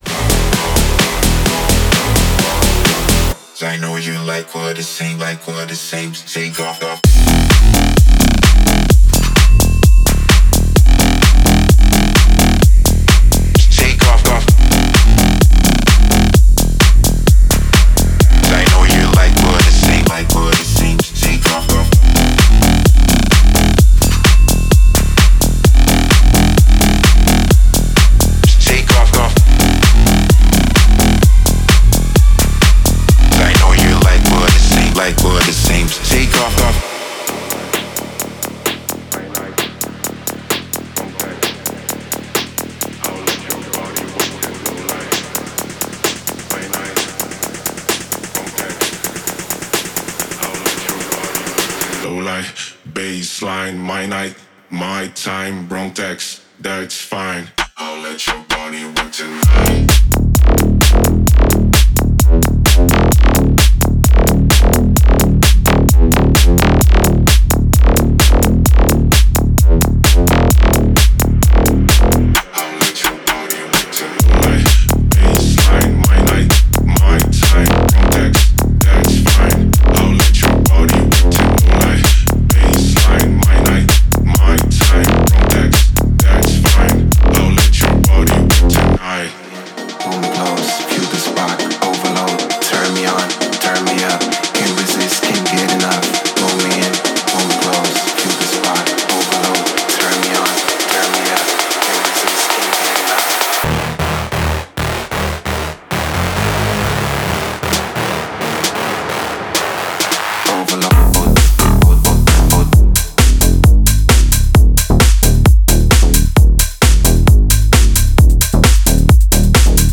Clean, punchy samples to create your own unique grooves: